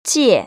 [jiè] 지에  ▶